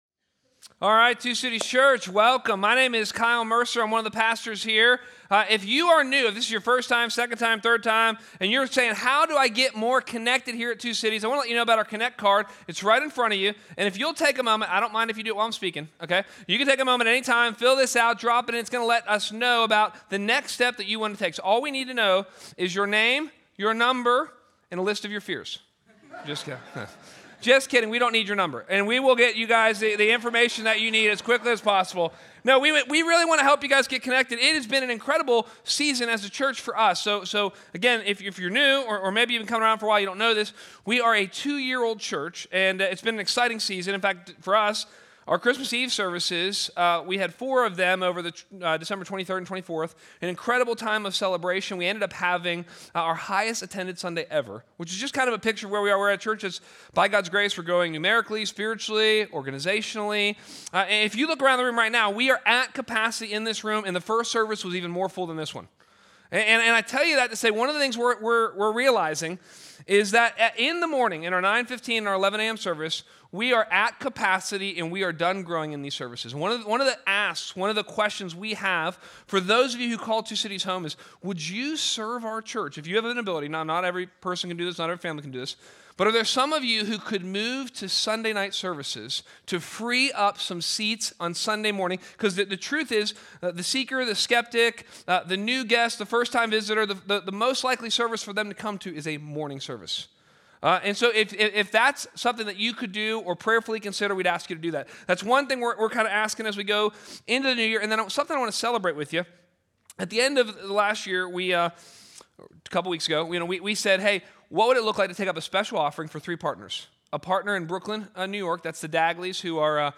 A message from the series "The Book of Judges."